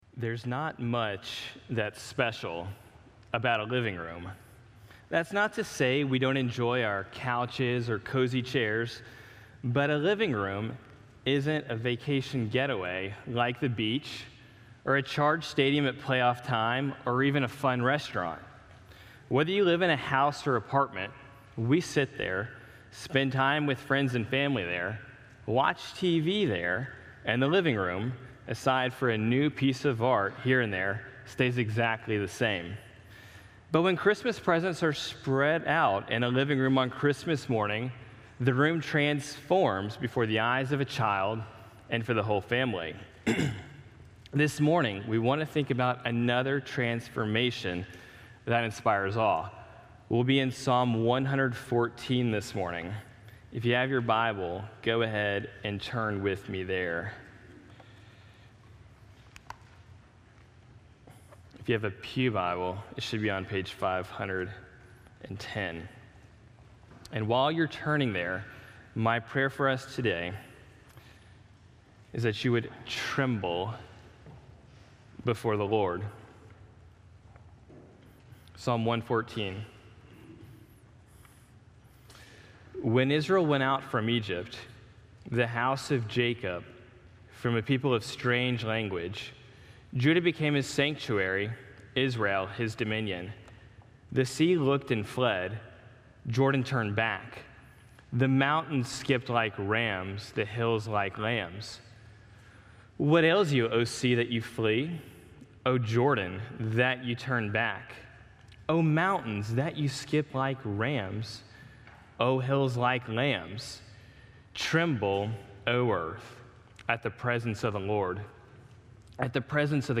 Livestream Video & Sermon Audio